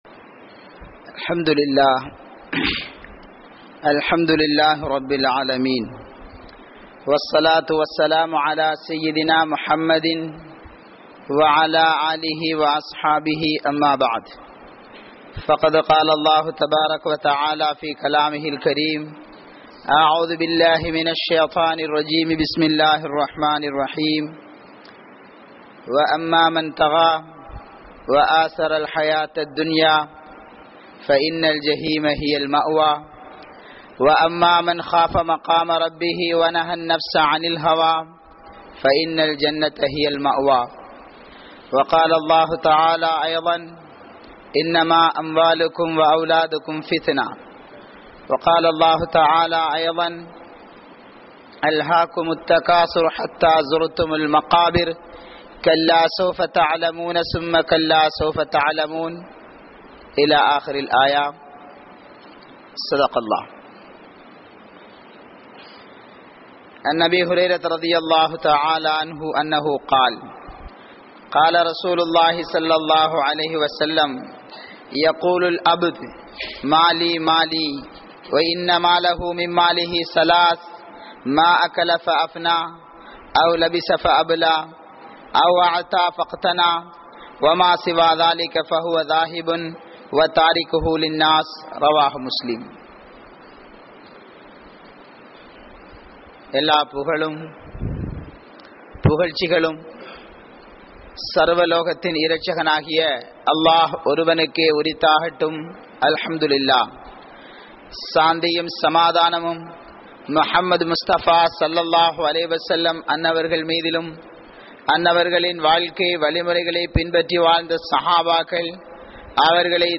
Alium Inpangal(Critically pleasure) | Audio Bayans | All Ceylon Muslim Youth Community | Addalaichenai
Kanampittya Masjithun Noor Jumua Masjith